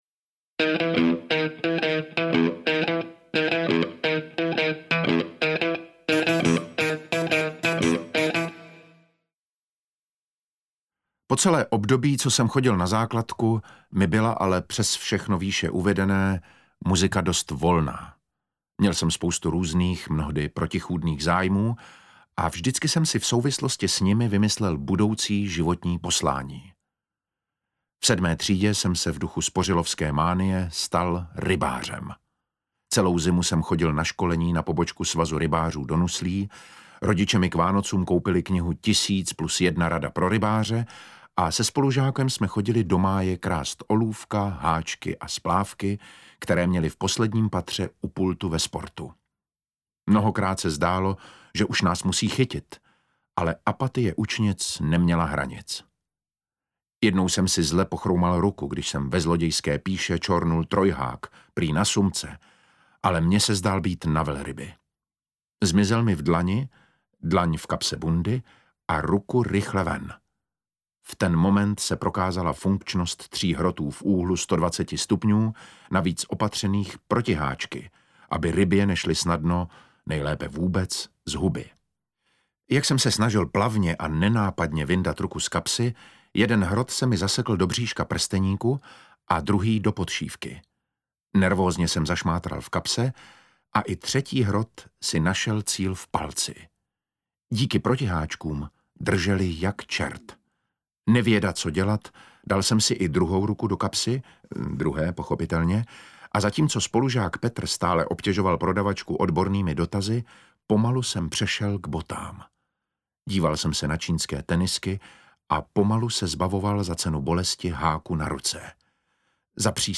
Tak to bylo, tak to je audiokniha
Audiokniha Tak to bylo, tak to je- audioknižní verze knih Tak to bylo, tak to je a Járo, kakao, jejichž autorem je Lou Fanánek Hagen, frontman skupiny Tři sestry. Čte David Matásek.
Ukázka z knihy